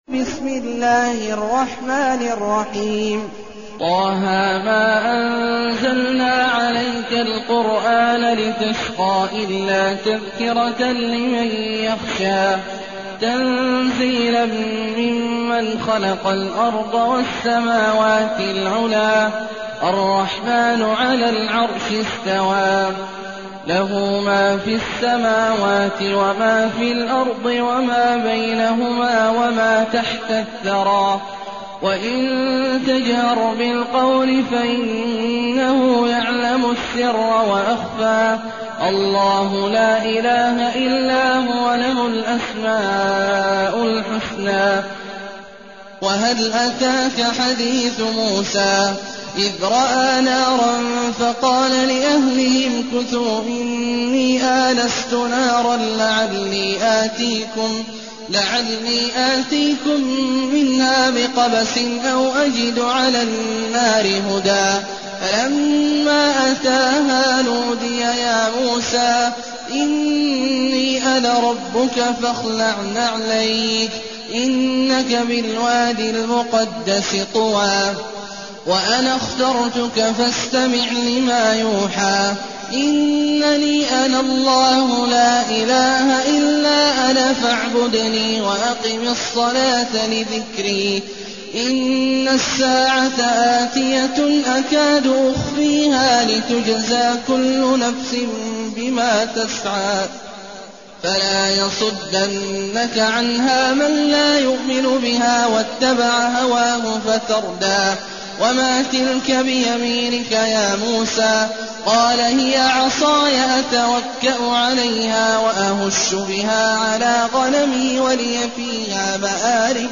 المكان: المسجد النبوي الشيخ: فضيلة الشيخ عبدالله الجهني فضيلة الشيخ عبدالله الجهني طه